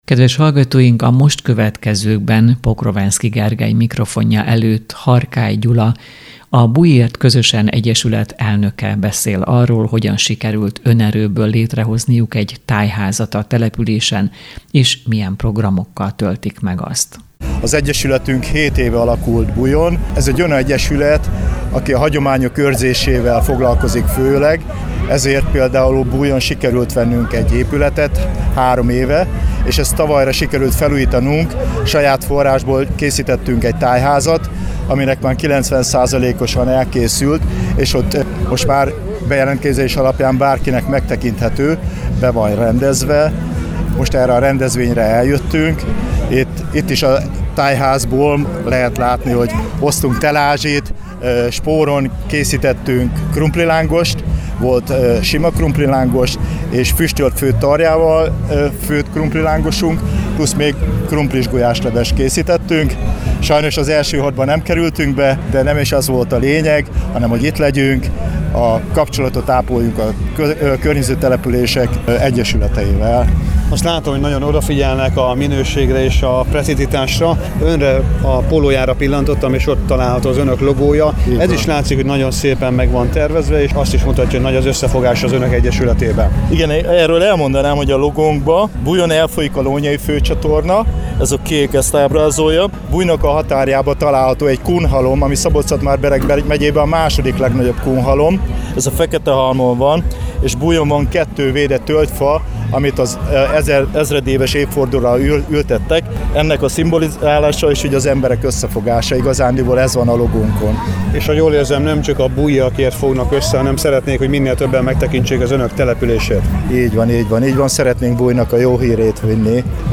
Egy kis település, nagy összefogás – erről szól mai riportunk.